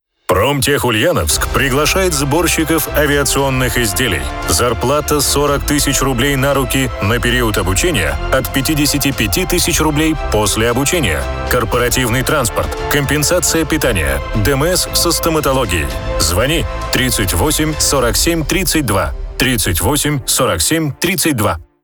Началось размещение рекламы на радиостанции "Русское радио" компании "ПРОМТЕХ-Ульяновск" в г. Ульяновске.